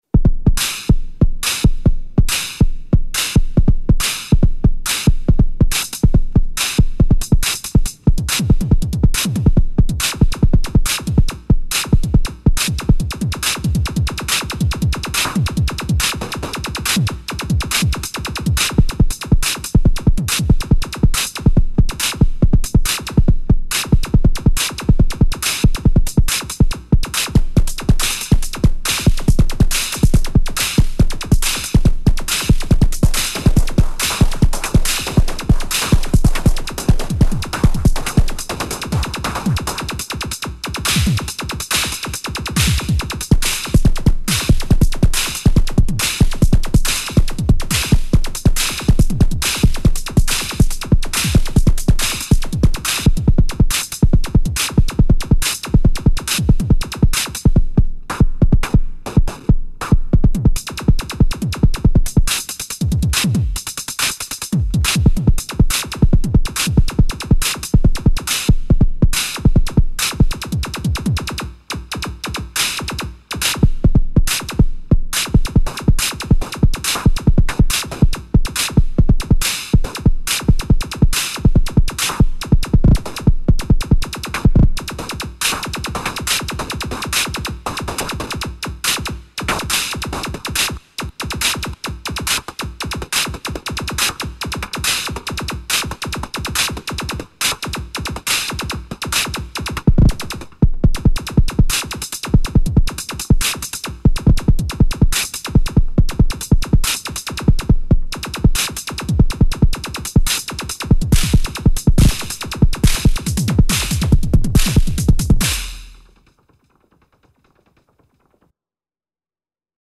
Volca drum parmigiana jam on the kitchen
Looks delicious and sounds great!